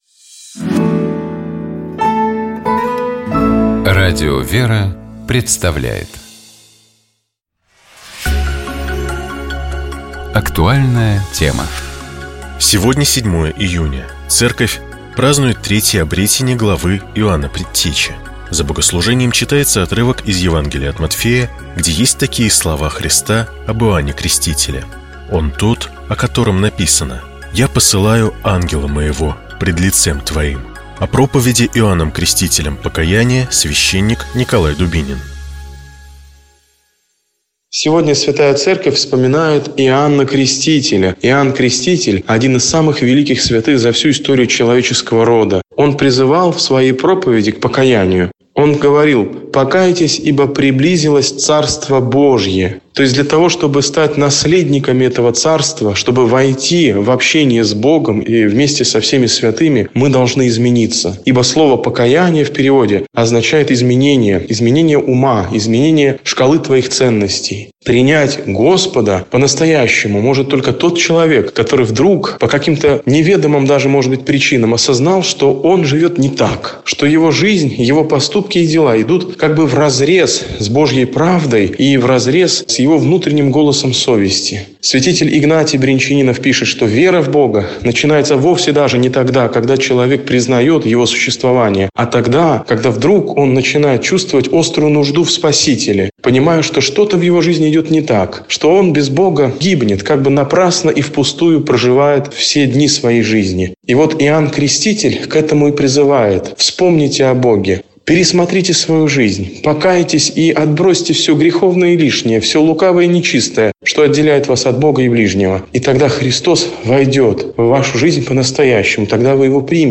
О проповеди Иоанном Крестителем покаяния, — священник